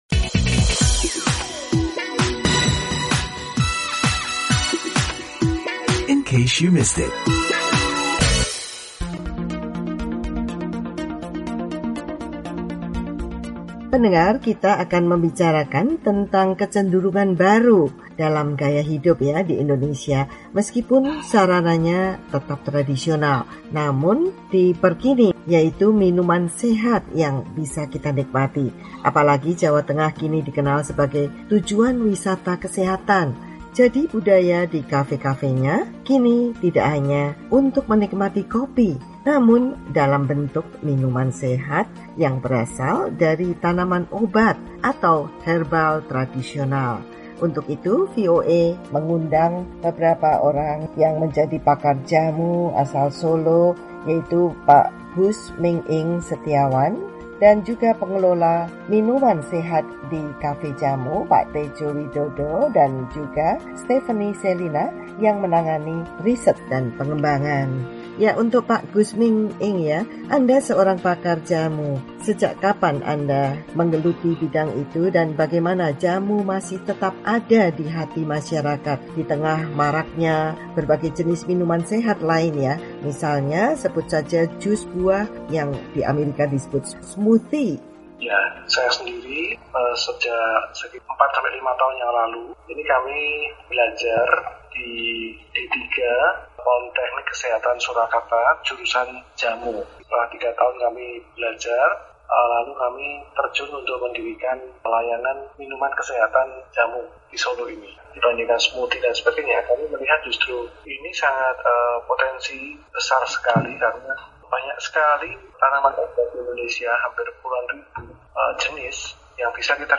berbincang dengan pakar jamu